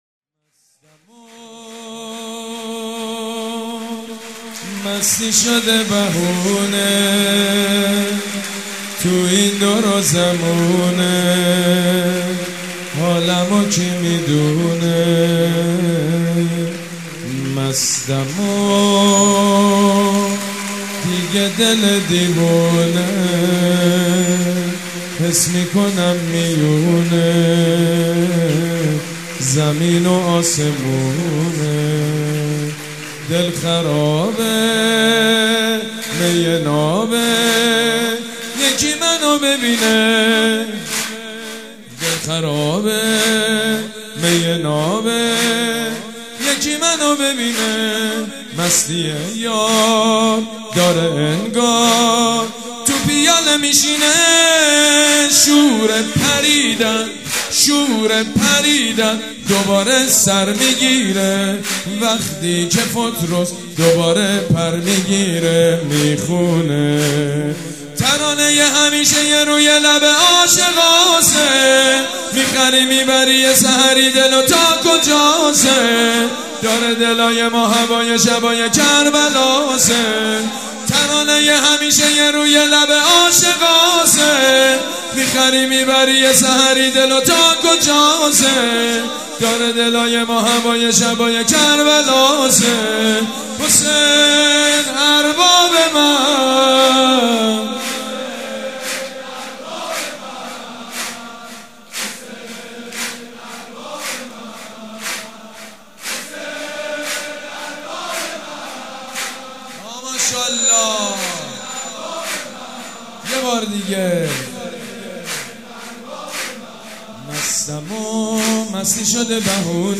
سرود: مستی شده بهونه